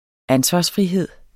Udtale [ ˈansvɑs- ]